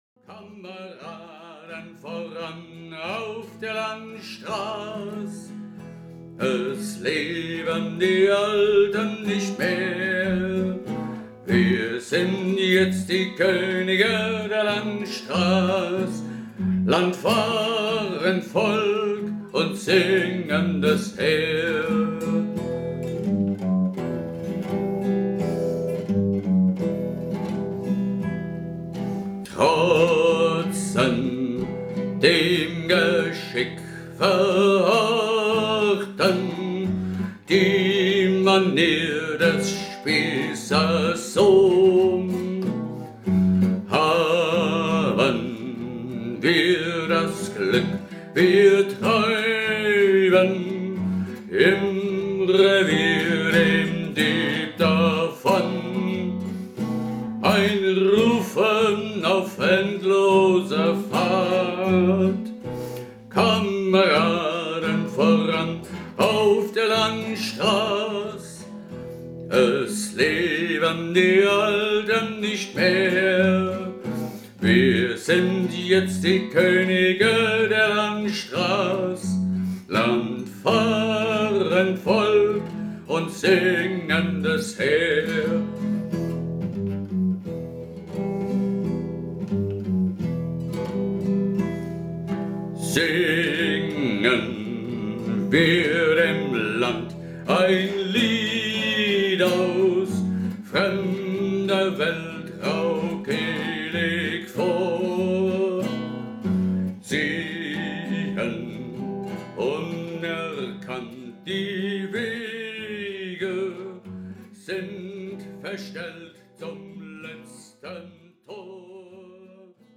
Die Hörprobe stammt von einer Musik-Kassettenaufnahme aus dem Jahr 2018.